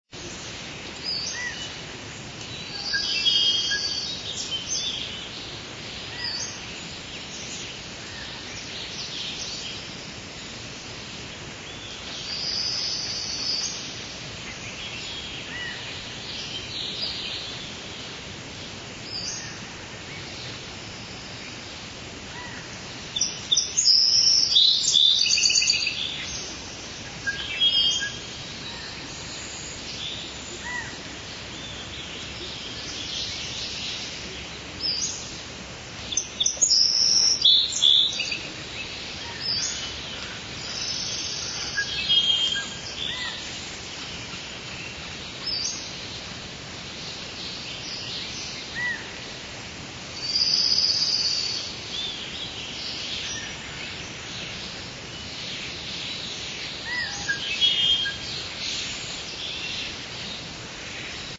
Nature Ambience.ogg